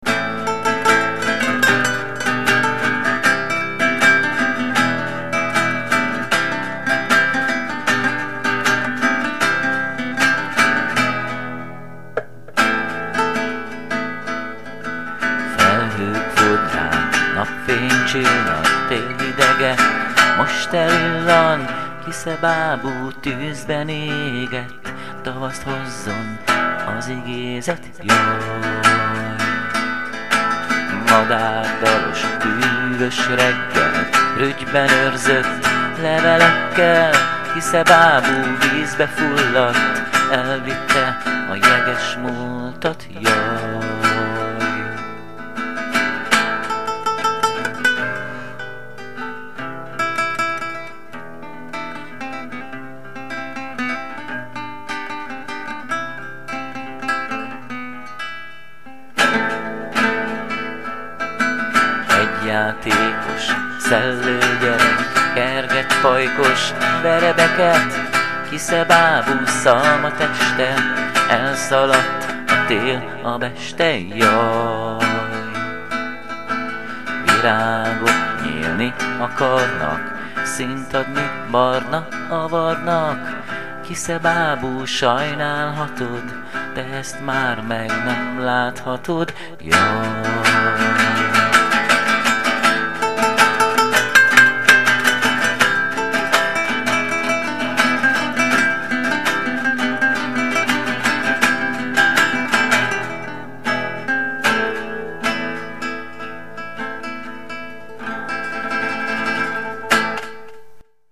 ének, furulya
gitár
hegedű